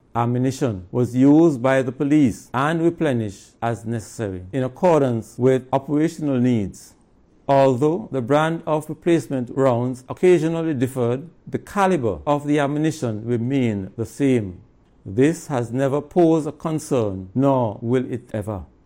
Commissioner Sutton said that this long standing arrangement continues to work efficiently: